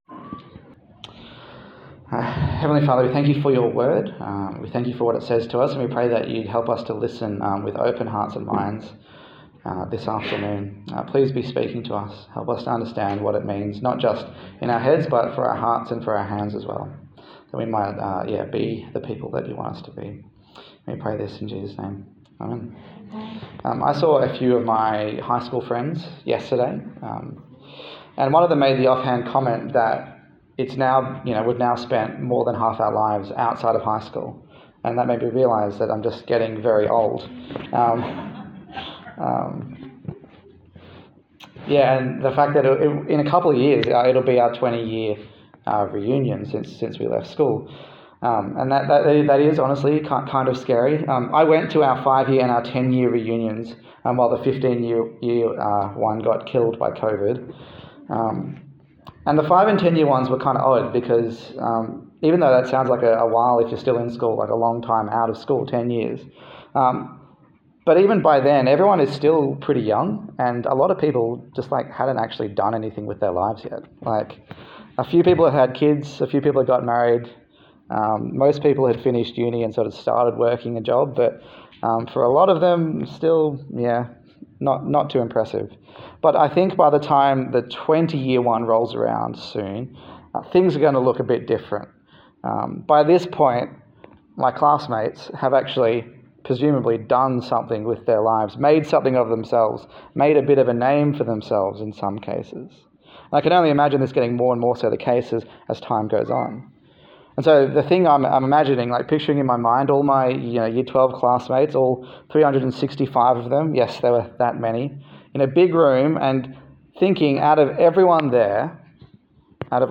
Hebrews Passage: Hebrews 12:4-29 Service Type: Sunday Service